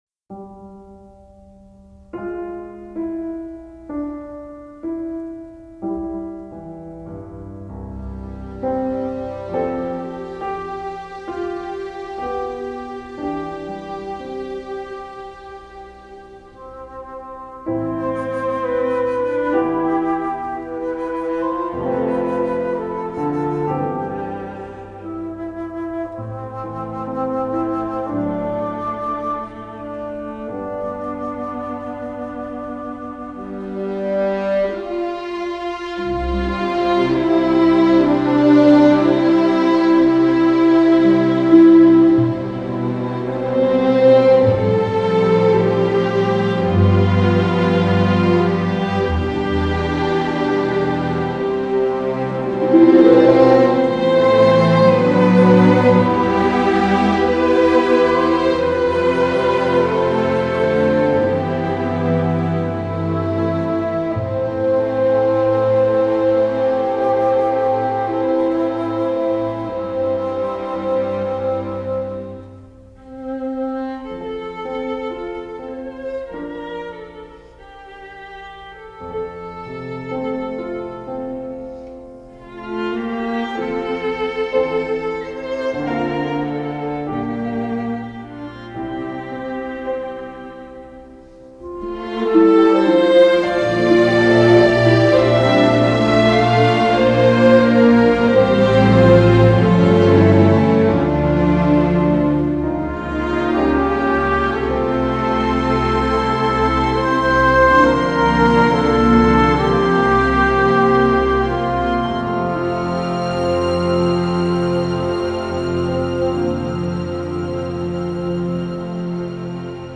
2002   Genre: Soundtrack   Artist